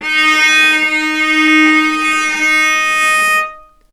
healing-soundscapes/Sound Banks/HSS_OP_Pack/Strings/cello/sul-ponticello/vc_sp-D#4-ff.AIF at 01ef1558cb71fd5ac0c09b723e26d76a8e1b755c
vc_sp-D#4-ff.AIF